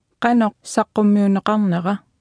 Below you can try out the text-to-speech system Martha.
Speech Synthesis Martha